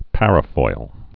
(părə-foil)